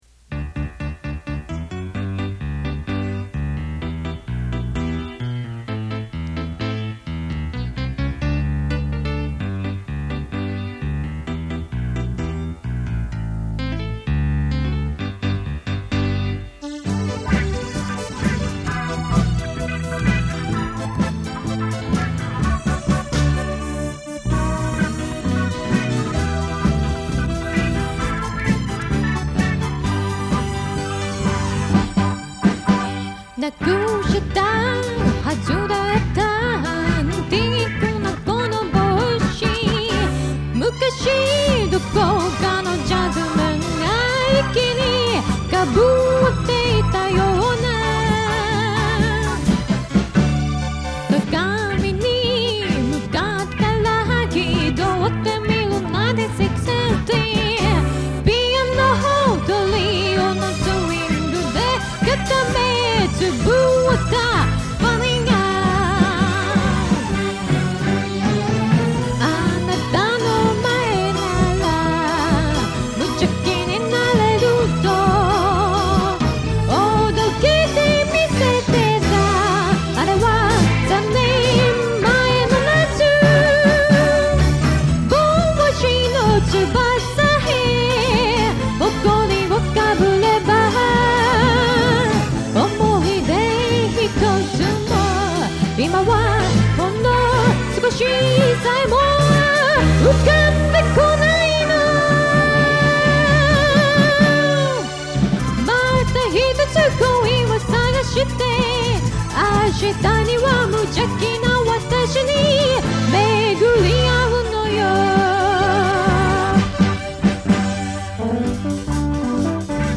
ここで紹介させていただきますのは，もう２０余年前，大学の頃，ひとり軽音楽部の部室で，多重録音で作ったオリジナル曲です。